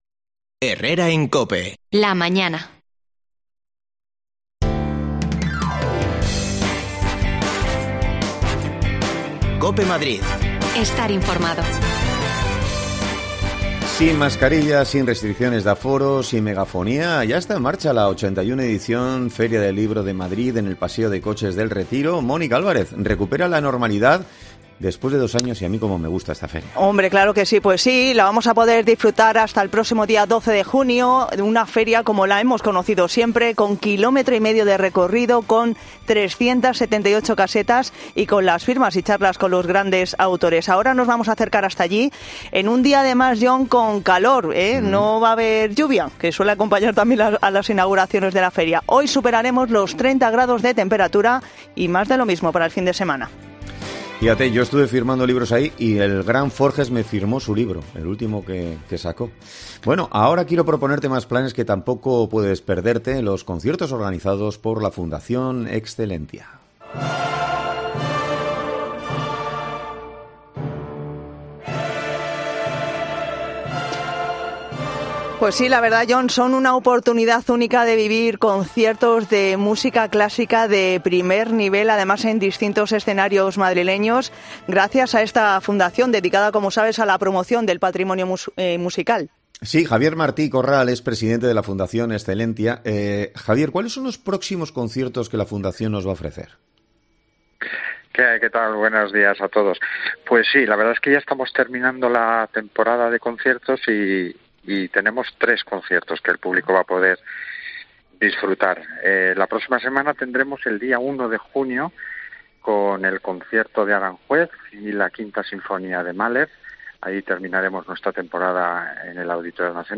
Nos acercamos hasta alli para hablar con visitantes y libreros